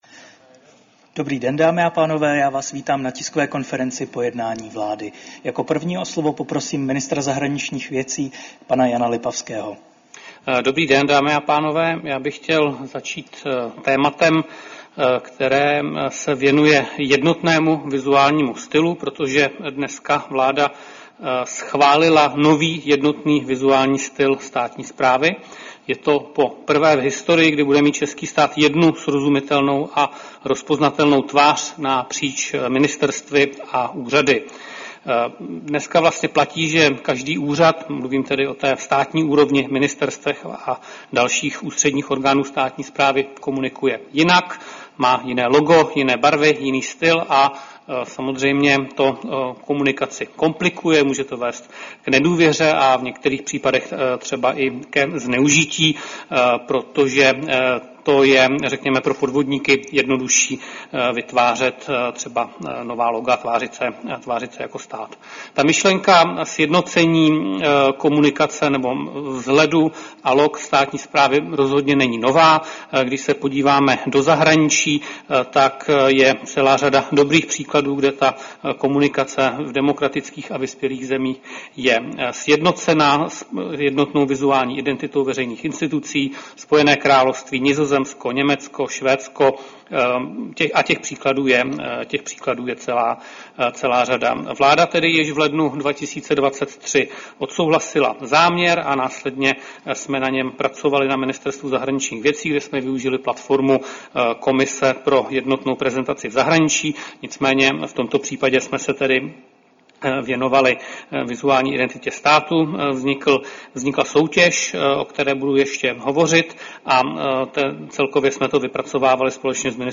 Tisková konference po jednání vlády, 16. července 2025